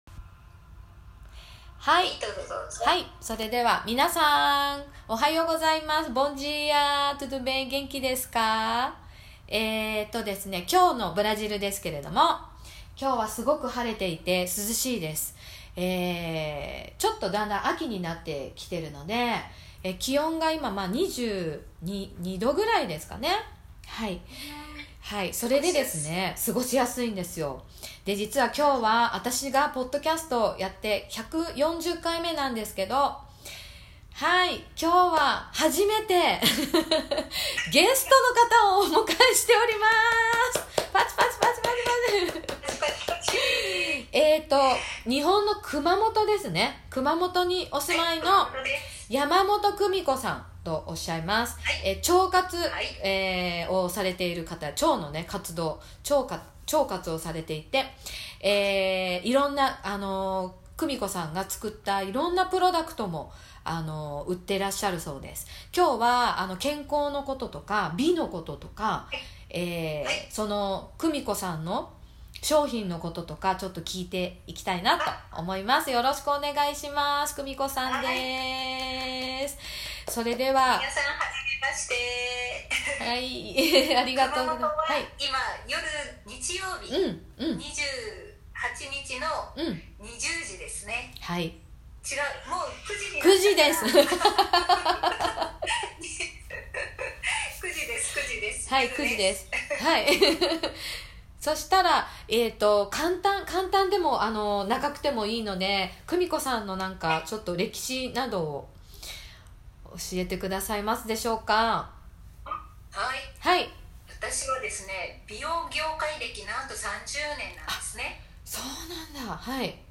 特別インタビュー